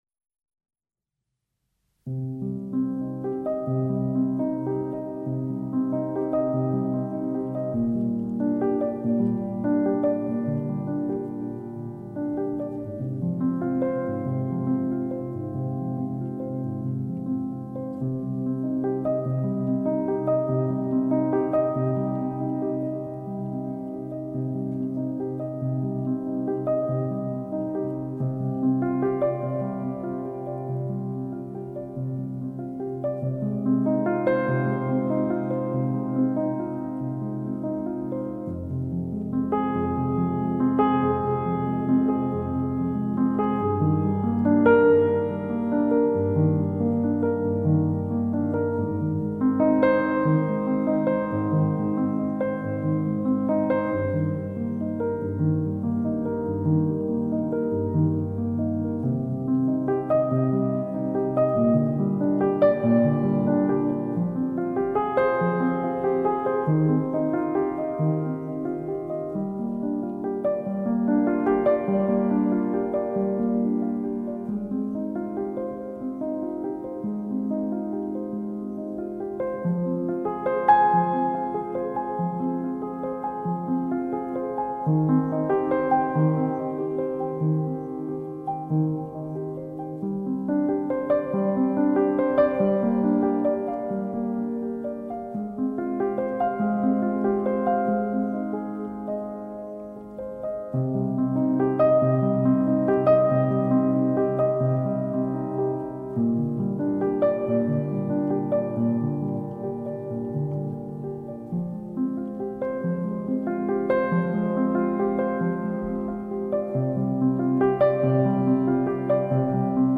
آرامش بخش
Classical Crossover
پیانو